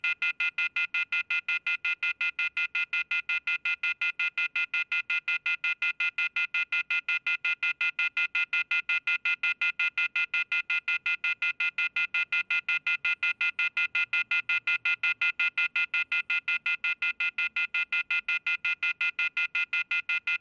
busy-phone-sound